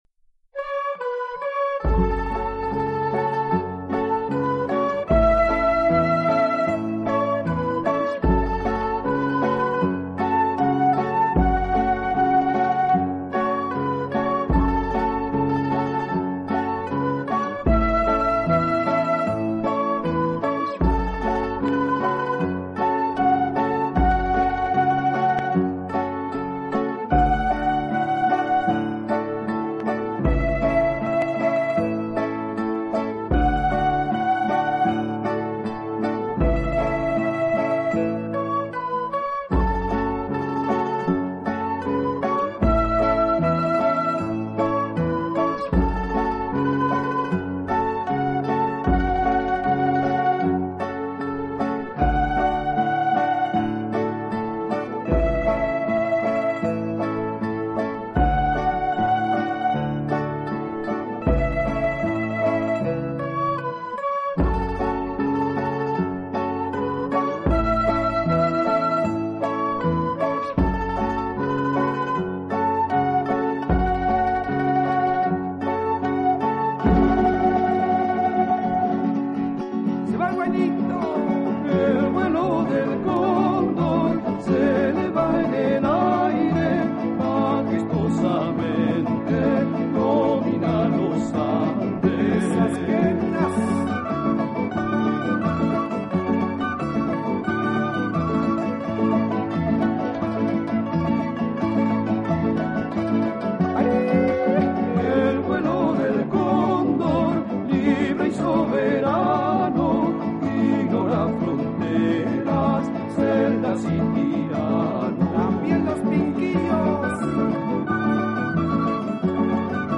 激动社区有关南美音乐（排箫）专辑地址索引